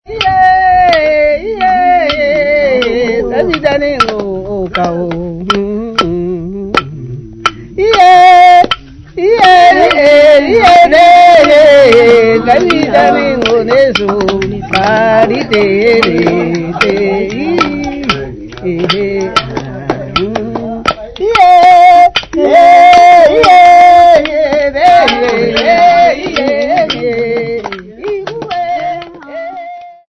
2 Young Ladies
Ovambo Folk
Choral music
Field recordings
Africa Namibia Walvis Bay f-sx
sound recording-musical
Indigenous music.